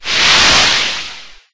Sand.ogg